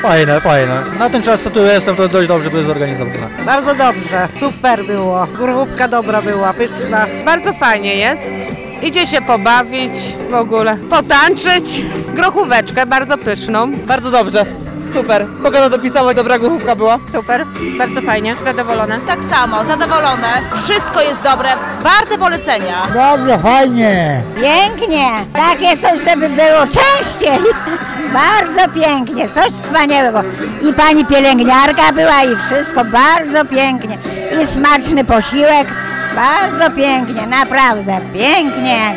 Swojego zadowolenia nie kryli też mieszkańcy Gorzyc i okolicznych miejscowości.